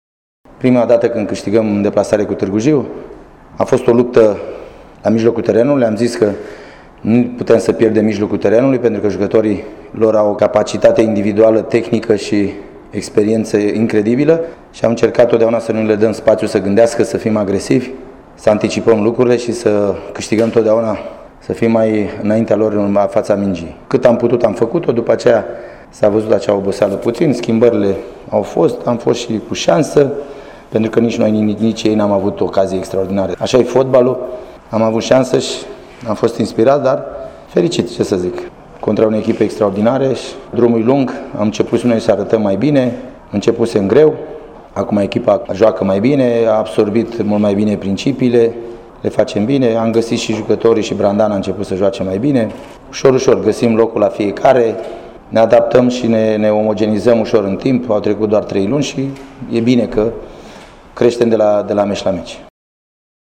Declaraţii după Pandurii Târgu-Jiu - FC Viitorul 0-1.